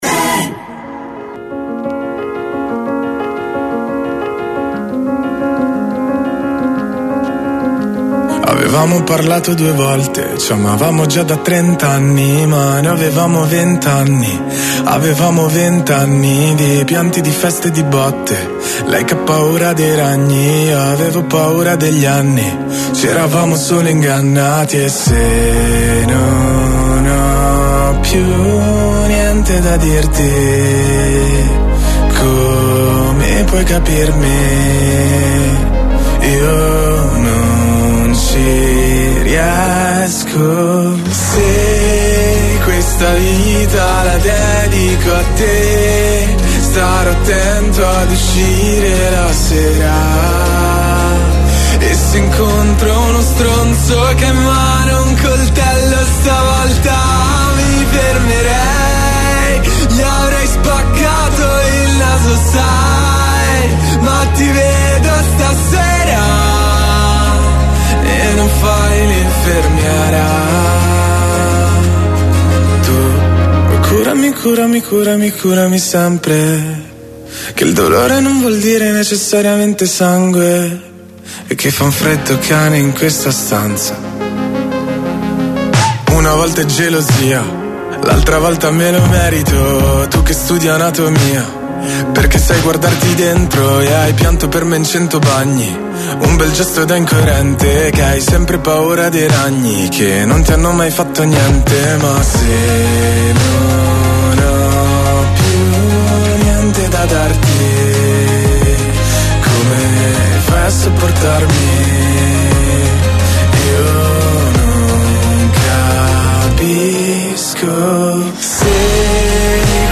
COLLEGAMENTI IN DIRETTA CON I CANALI TV ALL NEWS, OSPITI AL TELEFONO DAL MONDO DELLO SPETTACOLO, DELLA MUSICA, DELLA CULTURA, DELL’ARTE, DELL’INFORMAZIONE, DELLA MEDICINA, DELLO SPORT E DEL FITNESS.